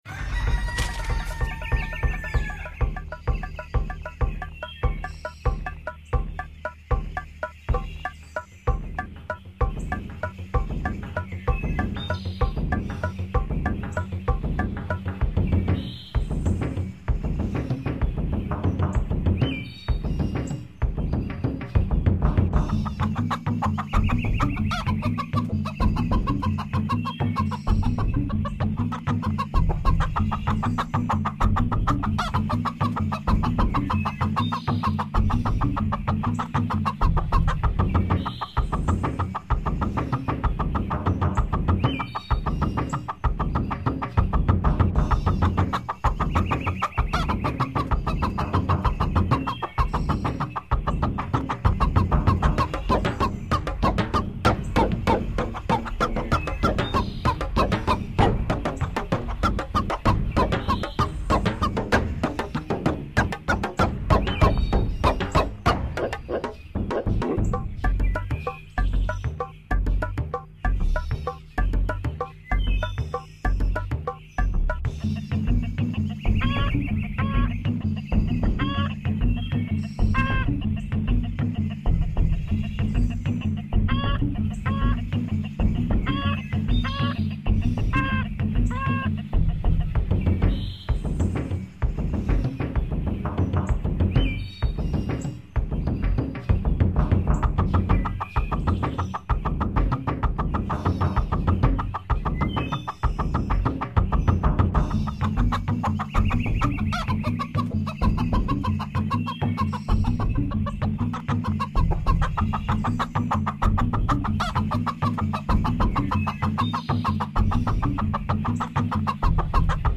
Samba organisée par un amat de poule!